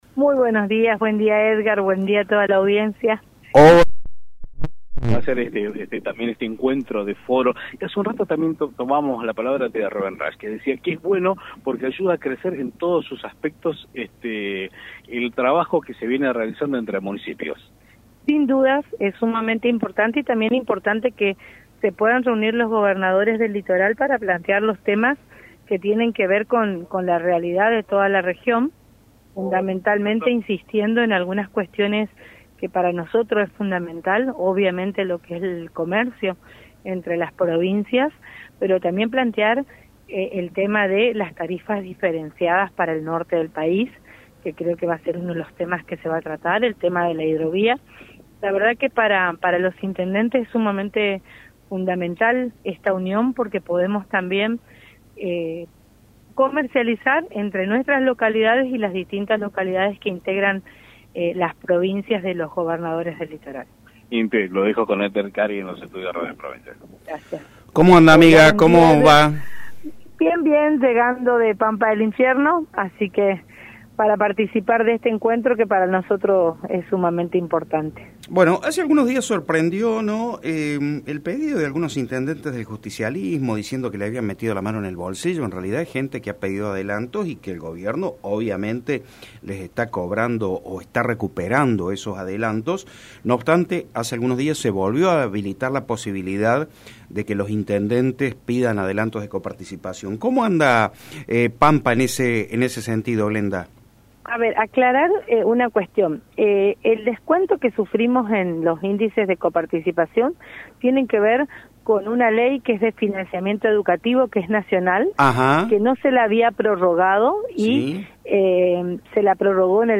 En diálogo con medios locales, Seifert abordó varios temas de actualidad, entre ellos la situación económica de los municipios, la falta de lluvias y la coyuntura política chaqueña.